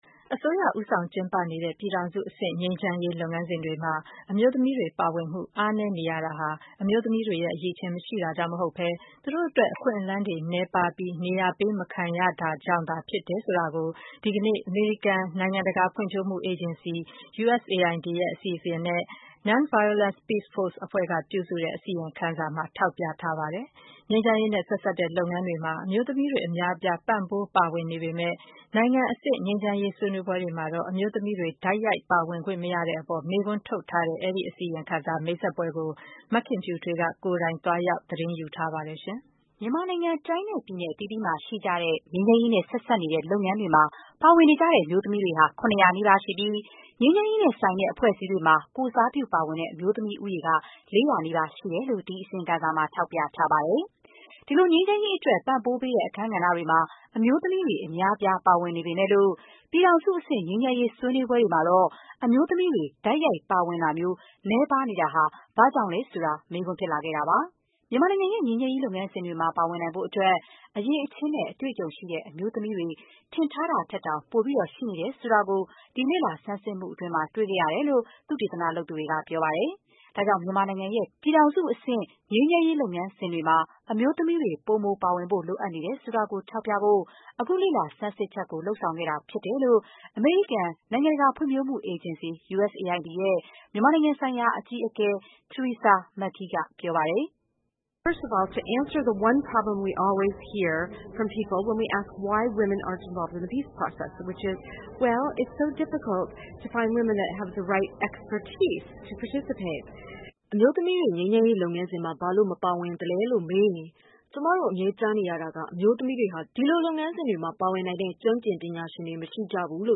Nonviolence Peaceforce အဖွဲ့ကပြုစုတဲ့ အစီရင်ခံစာ မိတ်ဆက်ပွဲ